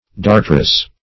Dartrous \Dar"trous\, a. [F. dartreux. See Dartars.] (Med.)